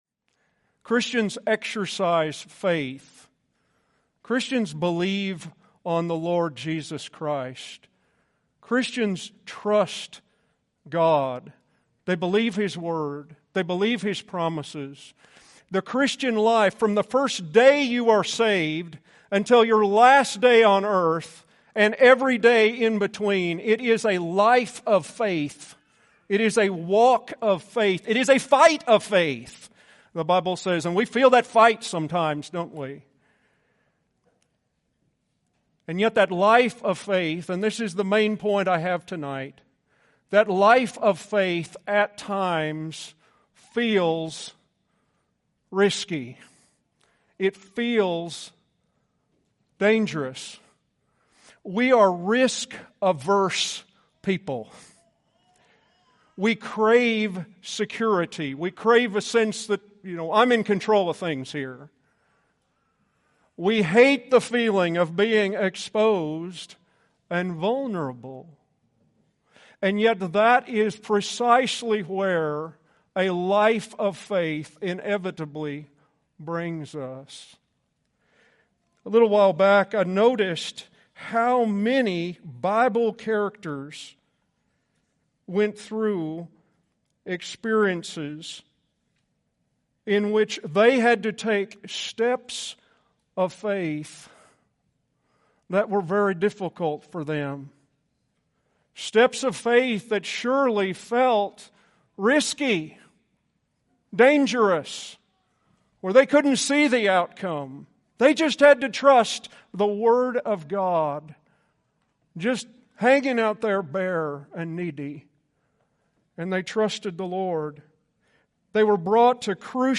2022 Category: Full Sermons At times the life of faith might feel risky and dangerous.